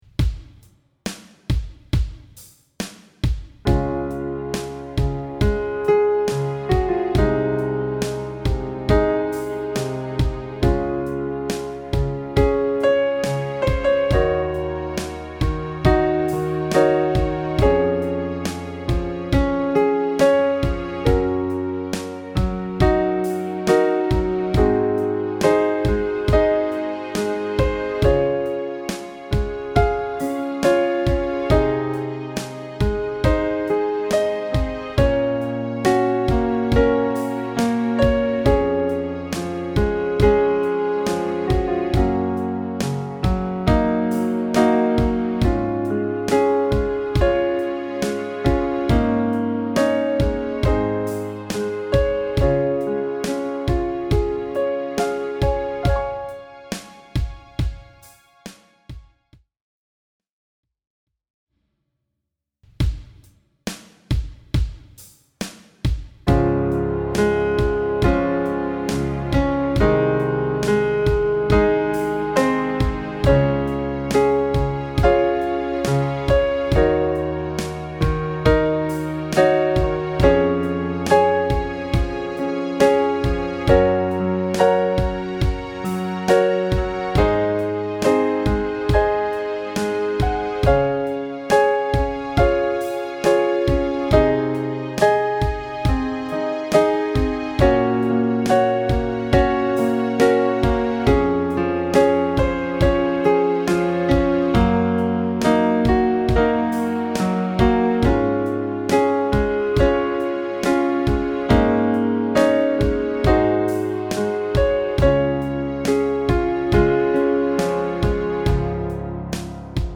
Tonleiterstudien zum Mitspielen:
Des-Dur (klingend)
des-dur.mp3